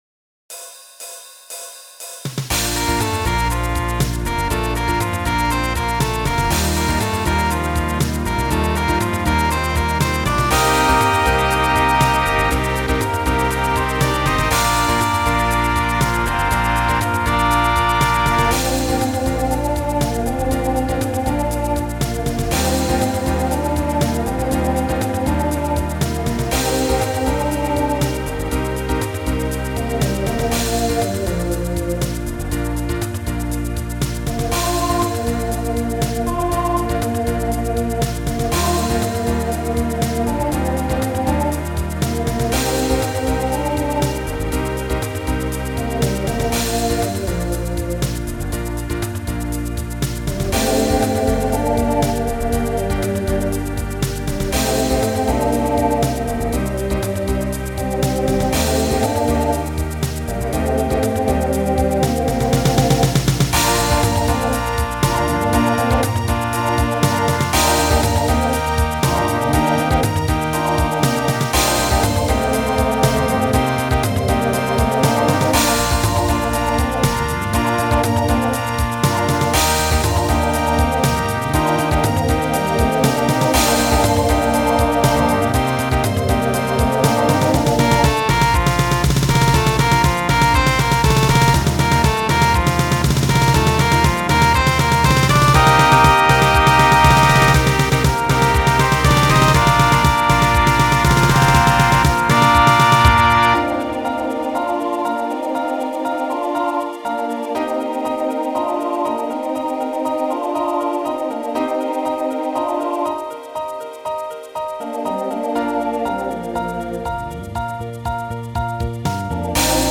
Voicing TTB Instrumental combo Genre Rock
Mid-tempo